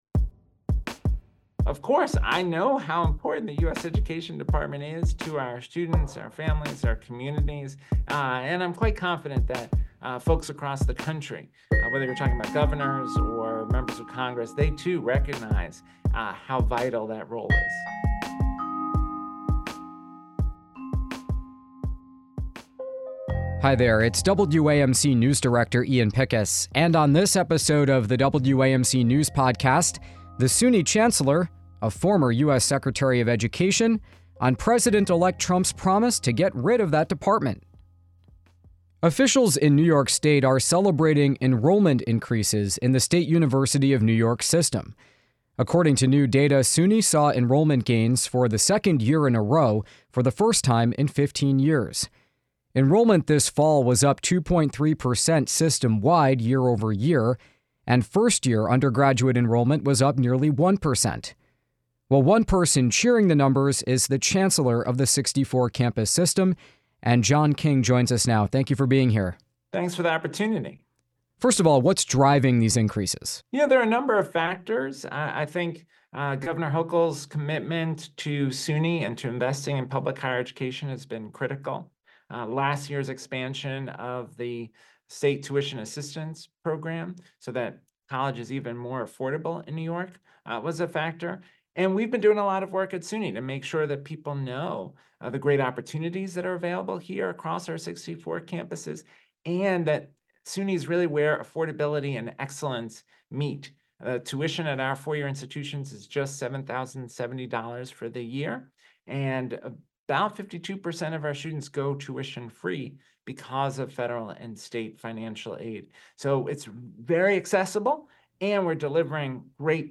We speak with SUNY Chancellor John King.